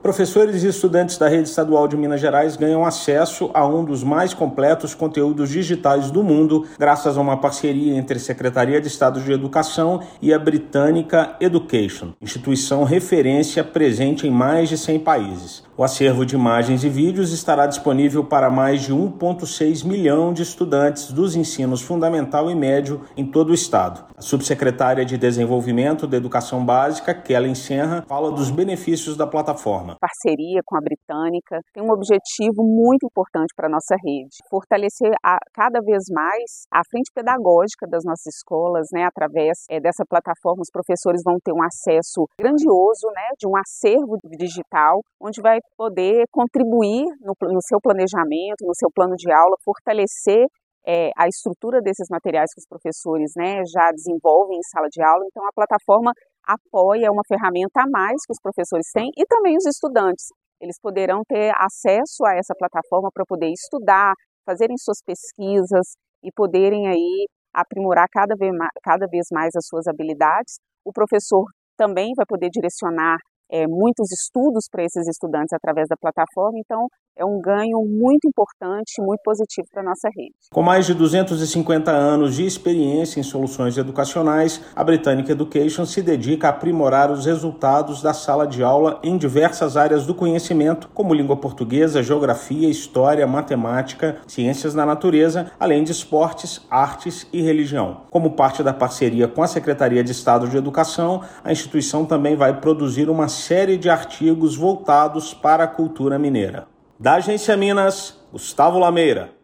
[RÁDIO] Professores e estudantes da rede estadual de Minas ganham acesso a um dos mais completos conteúdos digitais do mundo